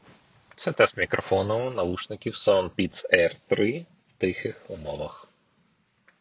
Мікрофон:
В тихих умовах: